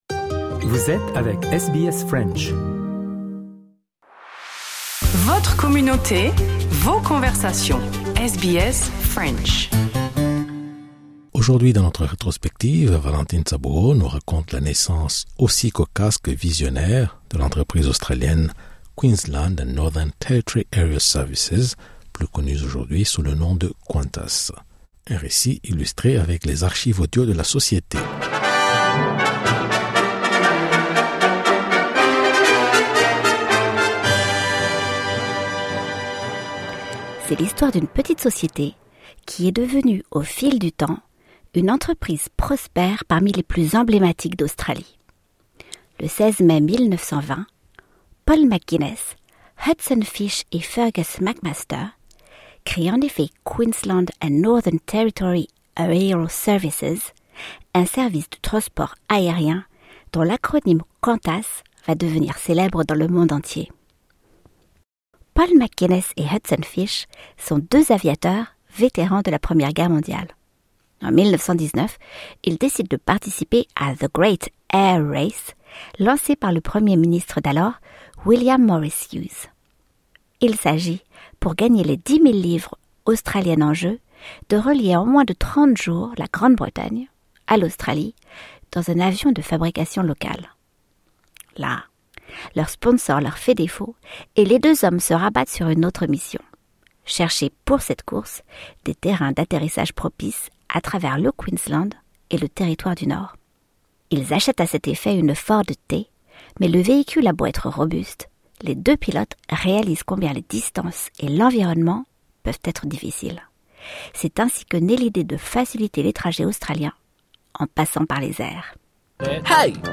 Un récit illustré avec les archives audio de la société.